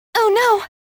女声oh no感叹音效免费音频素材下载